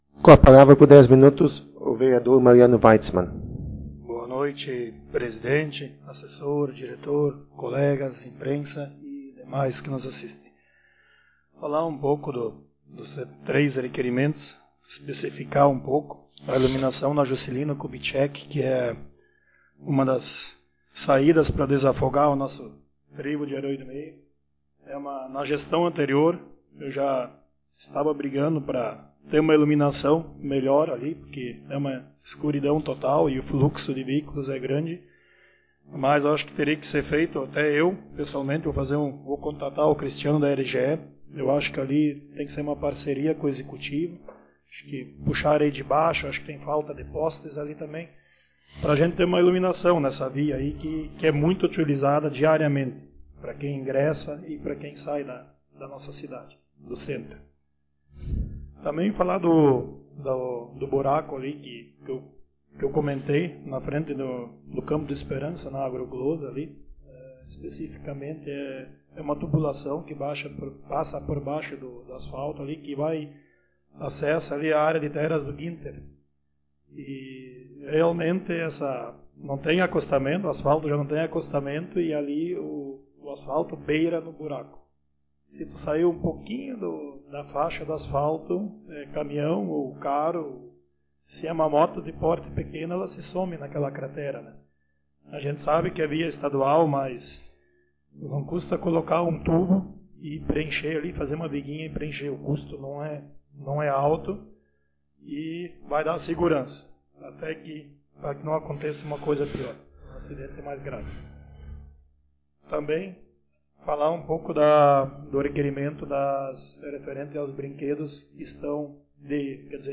Áudio das Sessões Vereadores